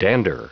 Prononciation du mot dander en anglais (fichier audio)
Prononciation du mot : dander